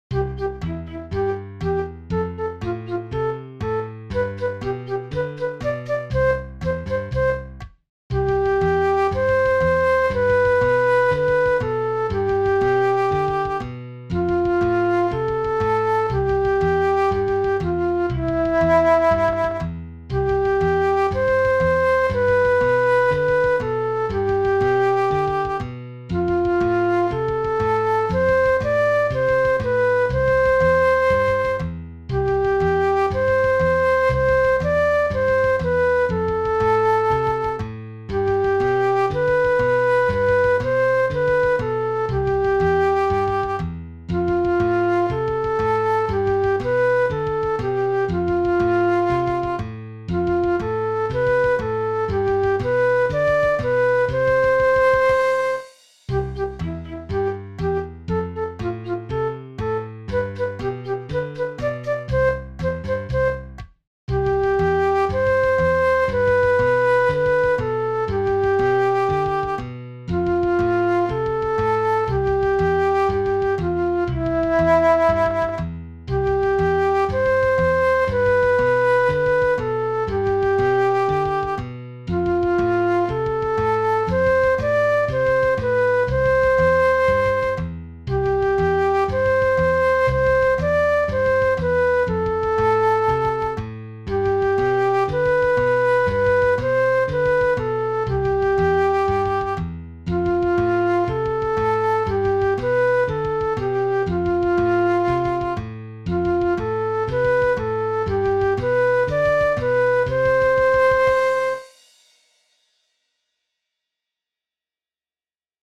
vi servirà  come base per cantare   o suonare